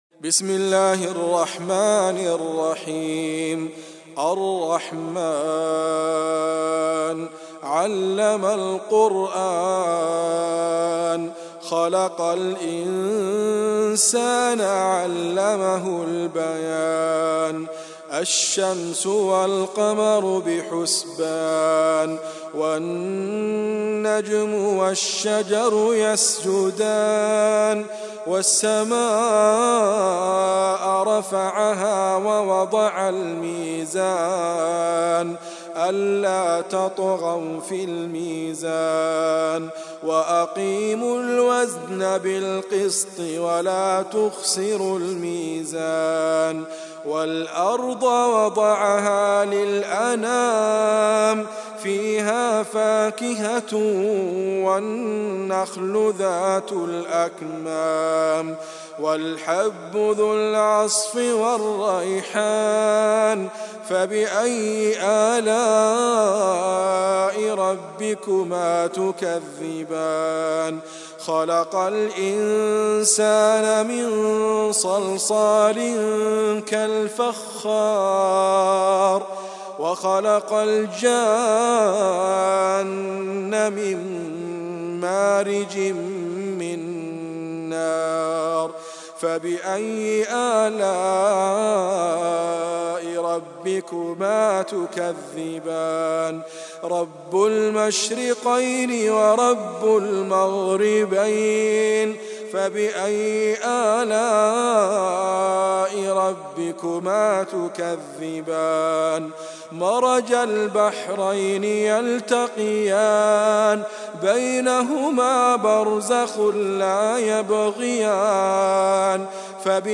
الرحمن ,ادريس ابكر ,القران الكريم